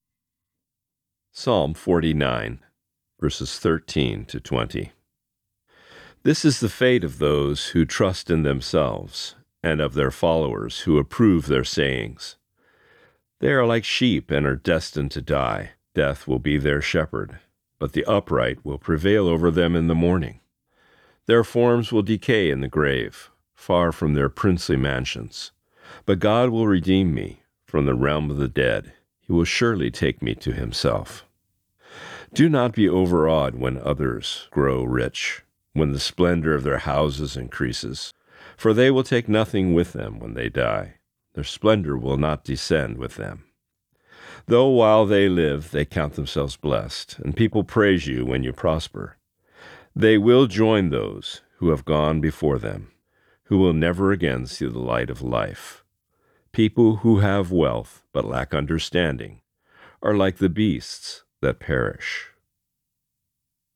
Today’s Reading: Psalm 49:13-20